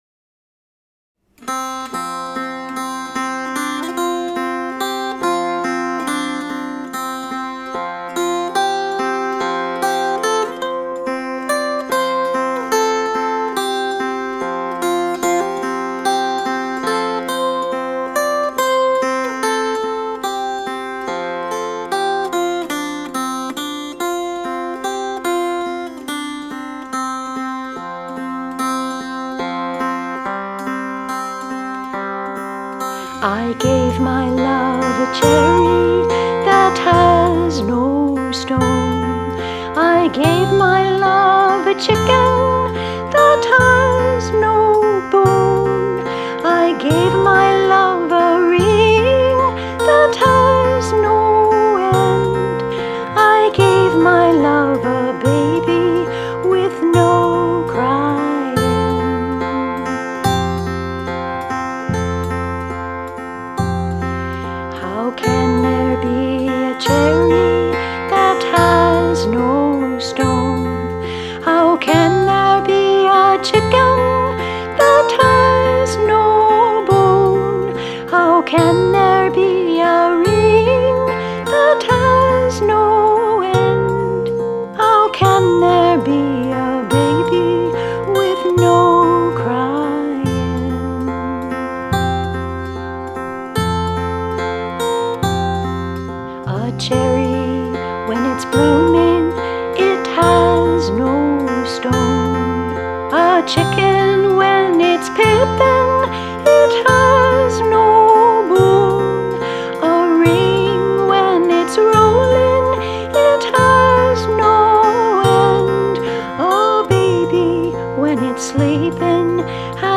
FREE MP3 Download - Full Performance
Categories: Children/Youth, Folk Music, Picture Books